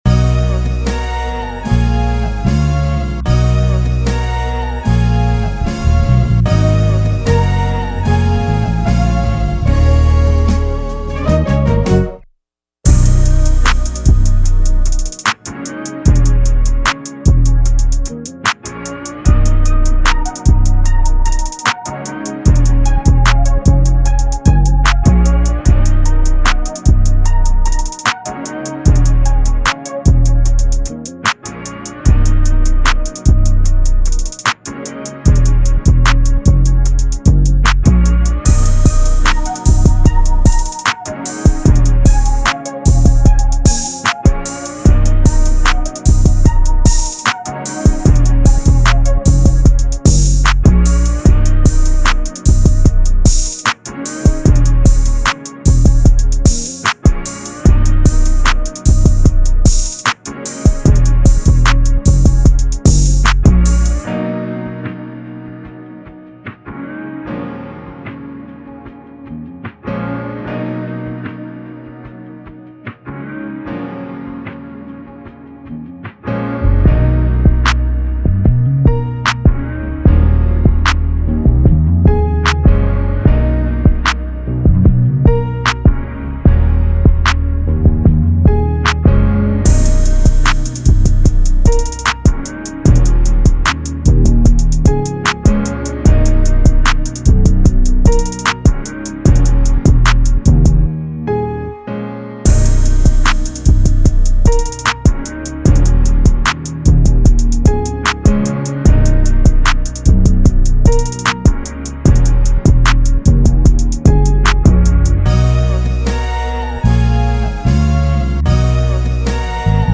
This is the official instrumental
Rap Instrumentals